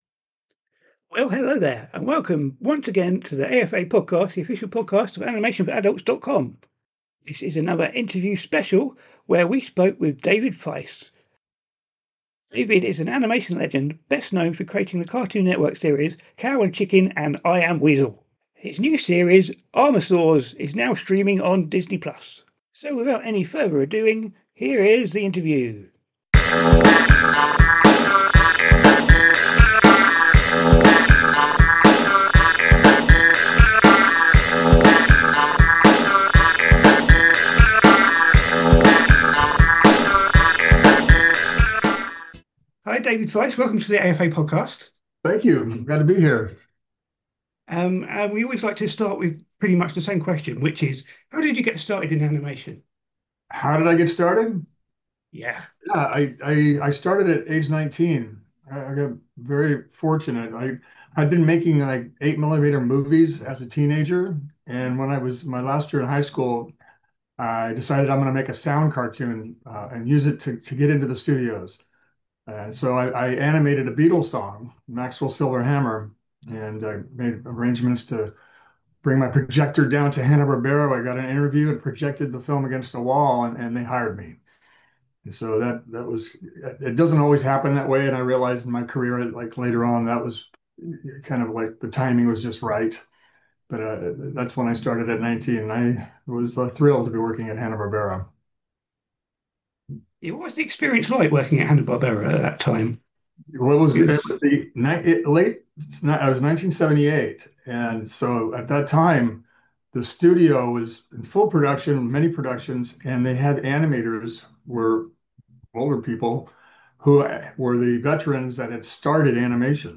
interview with the creator of Cow and Chicken and I Am Weasel.